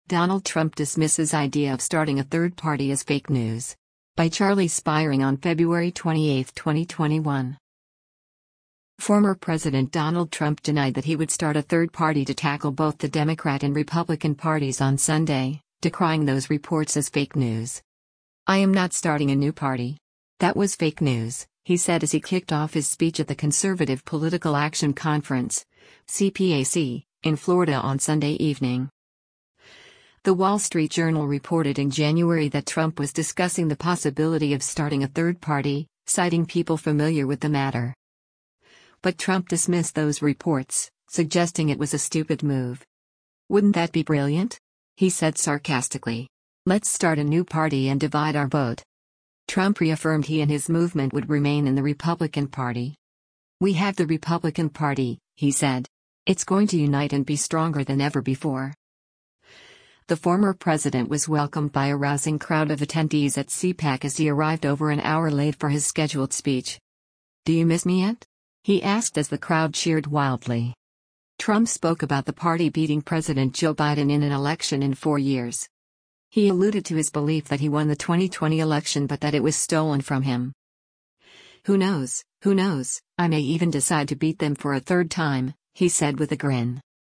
“I am not starting a new party. That was fake news,” he said as he kicked off his speech at the Conservative Political Action Conference (CPAC) in Florida on Sunday evening.
The former president was welcomed by a rousing crowd of attendees at CPAC as he arrived over an hour late for his scheduled speech.
“Do you miss me yet?” he asked as the crowd cheered wildly.